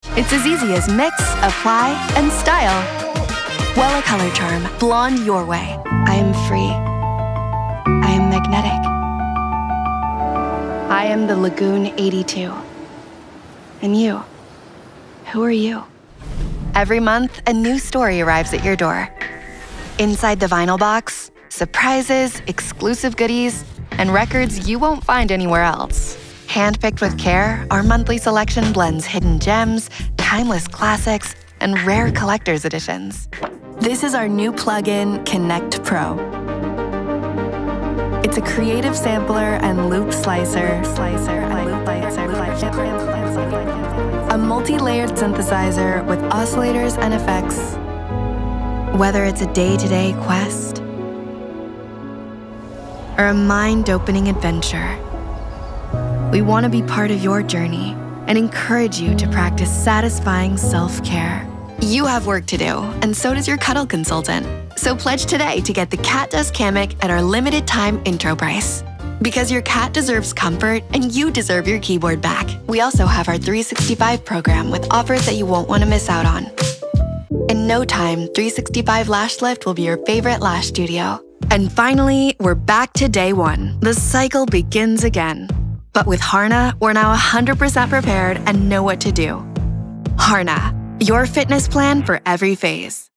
A young, fresh, feminine and soft voice with genuine warmth and clarity
Commercial Reel